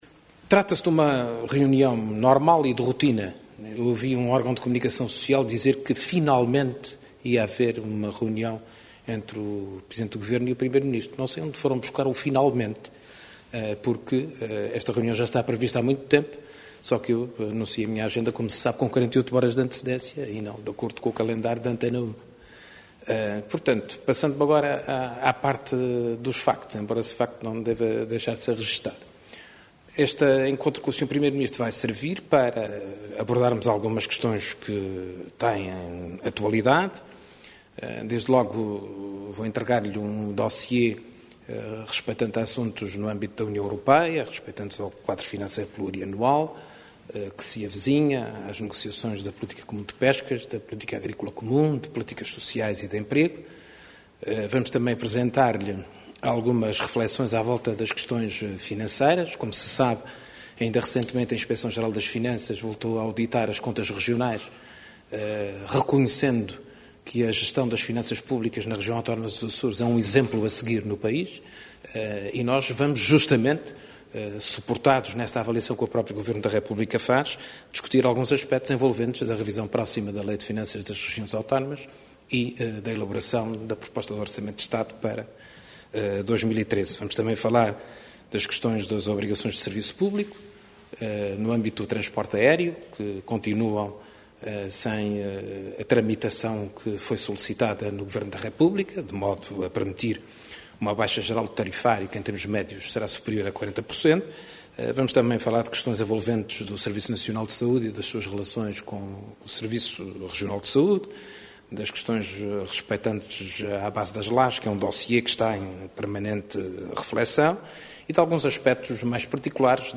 Carlos César antecipava, assim, em declarações aos jornalistas à margem de uma série de audiências hoje no Palácio de Sant´Ana, o propósito e a agenda da reunião com Pedro Passos Coelho.